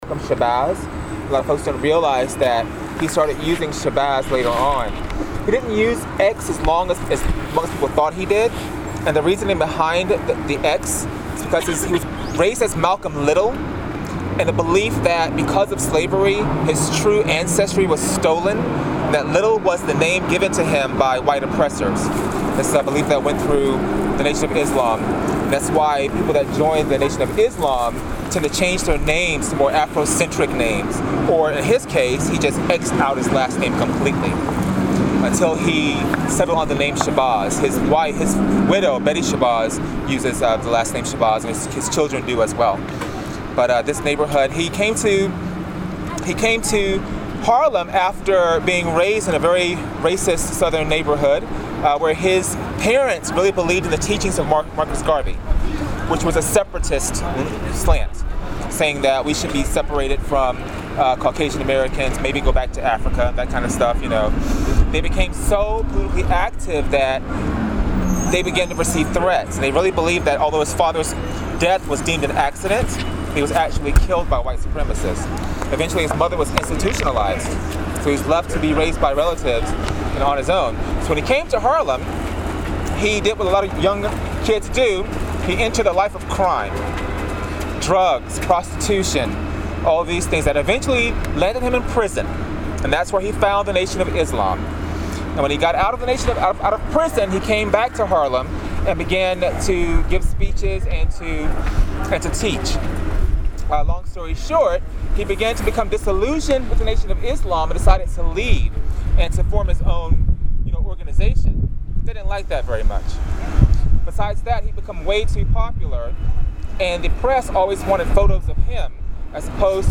our guides